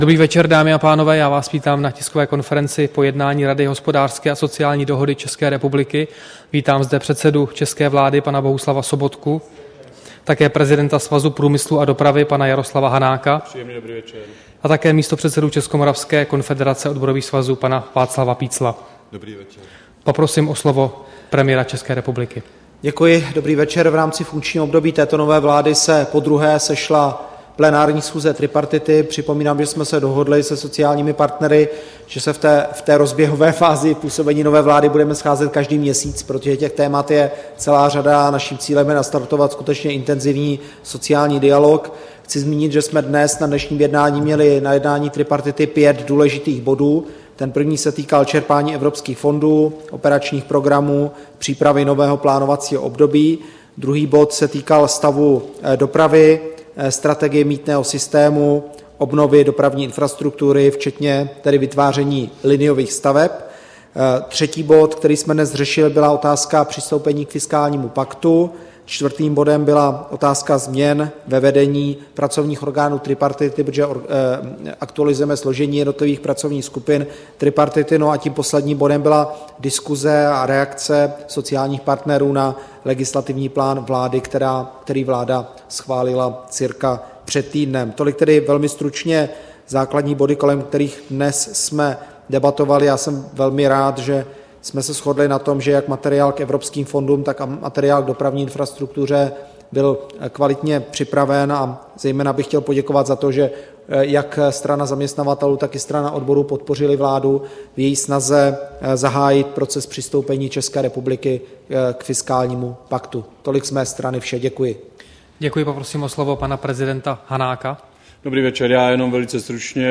Tisková konference po jednání triprartity, 17. března 2014